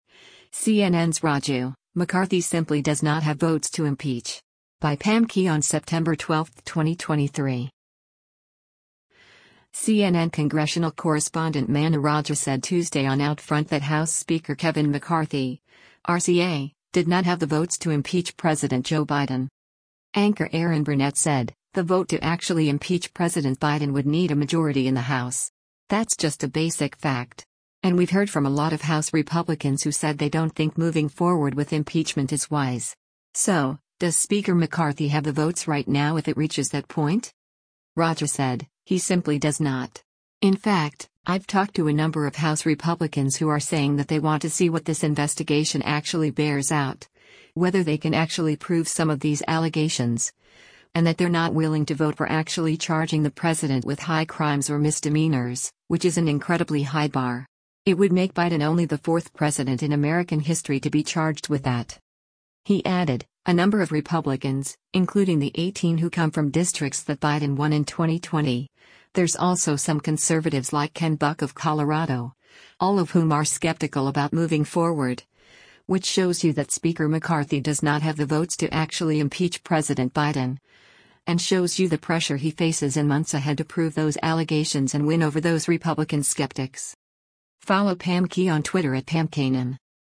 CNN congressional correspondent Manu Raju said Tuesday on “OutFront” that House Speaker Kevin McCarthy (R-CA) did not have the votes to impeach President Joe Biden.